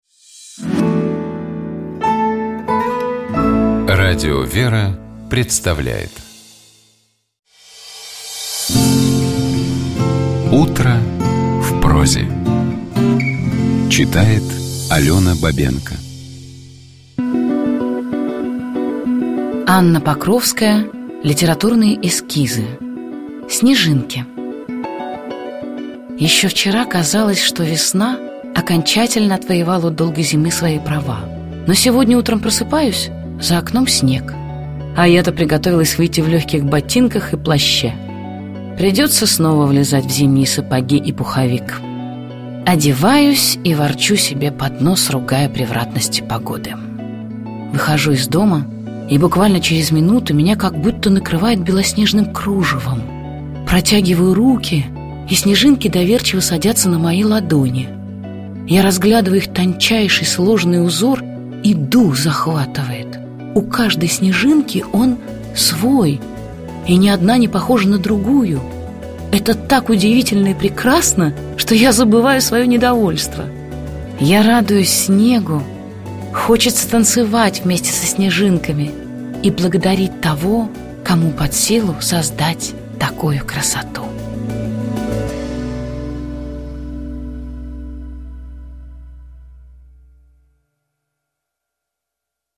Текст Анны Покровской читает Алёна Бабенко.